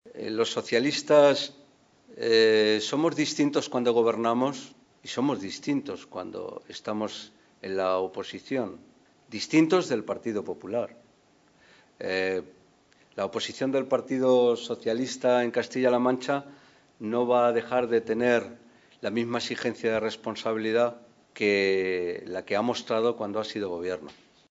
Fernando Moraleda, diputado nacional del PSOE
Cortes de audio de la rueda de prensa